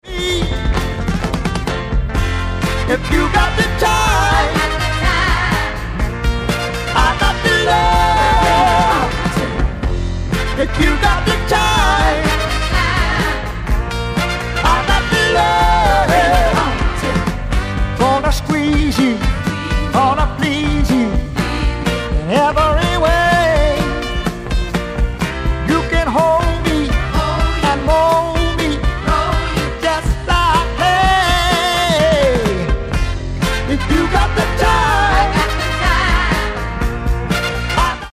ギター、ヴォーカル
オルガン、ヴォーカル、フロント・マン
ドラムス